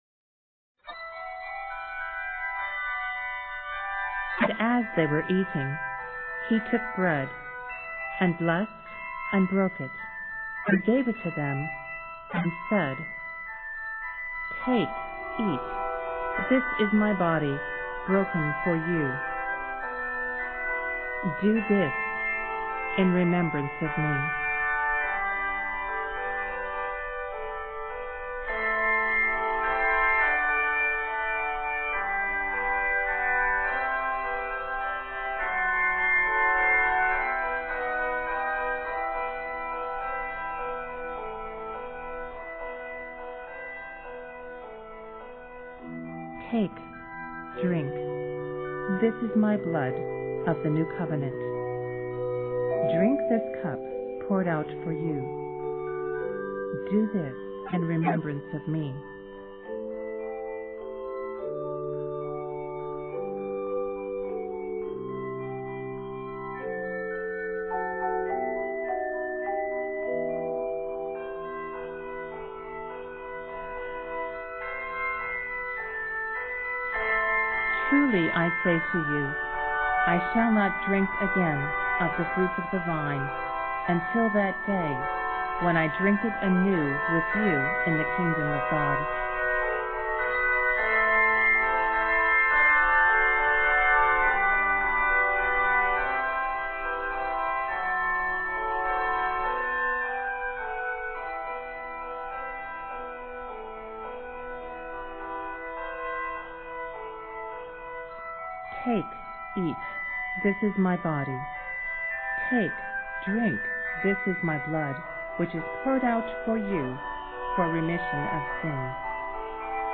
handbell piece